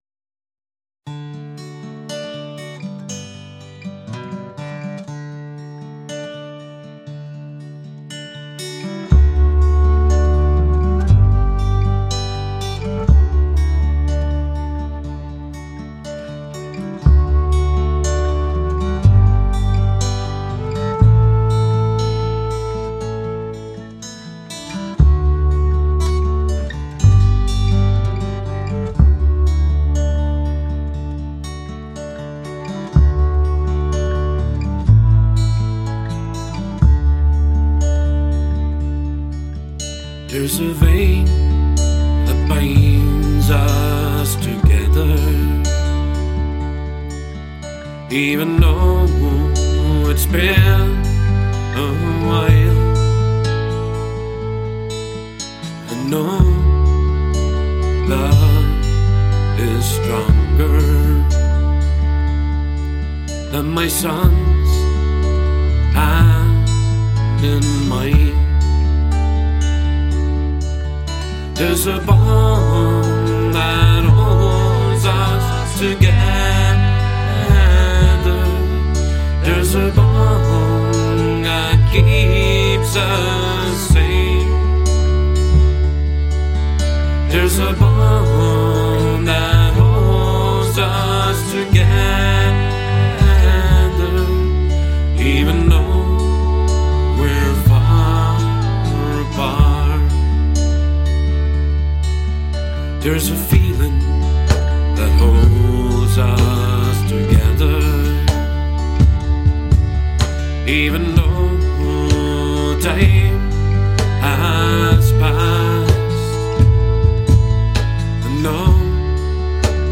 Folk music, - inspired by the greatest, with heart and soul
folk- pop- genren